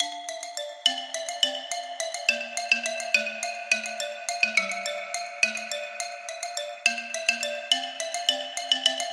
圣诞雪橇的钟声
声道立体声